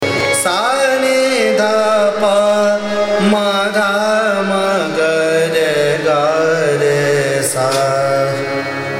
Raga
AvarohaS’ N d P M d M g r g r S